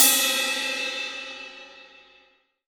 Index of /90_sSampleCDs/AKAI S6000 CD-ROM - Volume 3/Crash_Cymbal1/18_22_INCH_CRASH
DRY 18CRS2-S.WAV